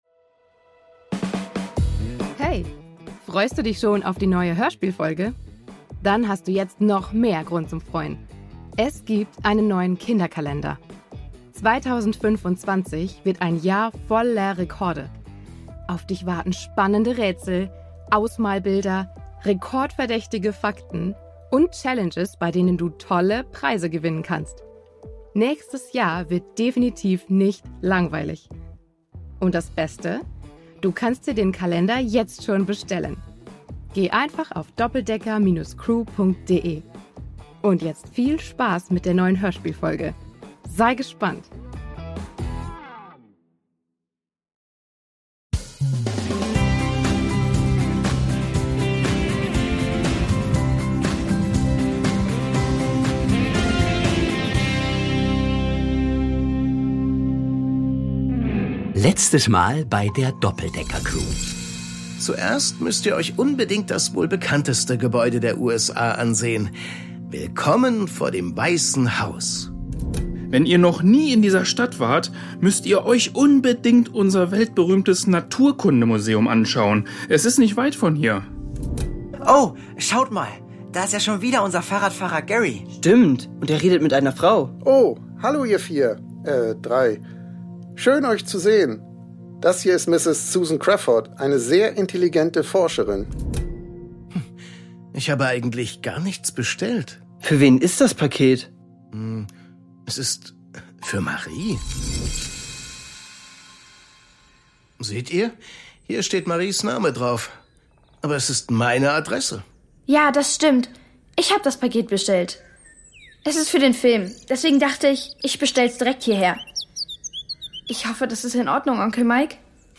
USA 2: Betrug im Museum (2/3) | Die Doppeldecker Crew | Hörspiel für Kinder (Hörbuch) ~ Die Doppeldecker Crew | Hörspiel für Kinder (Hörbuch) Podcast